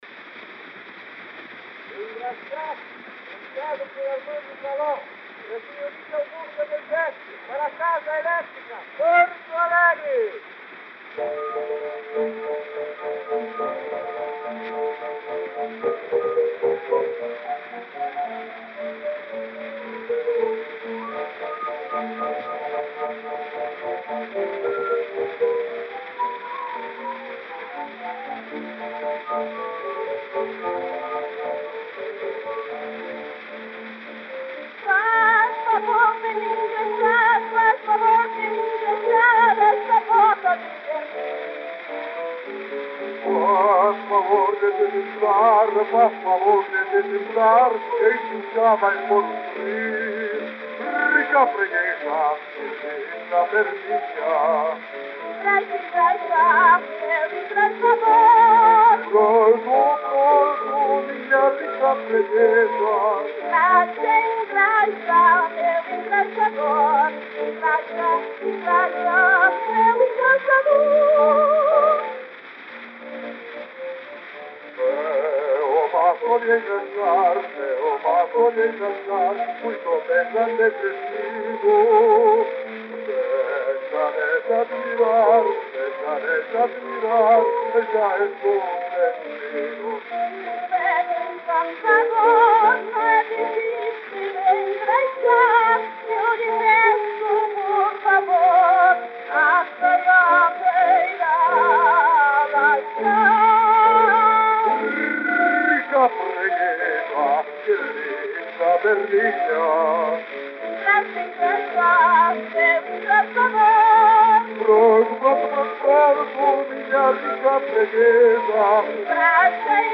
Disco de 78 rotações, também chamado "78 rpm", gravado em apenas um dos lados e com rótulo "tricolor".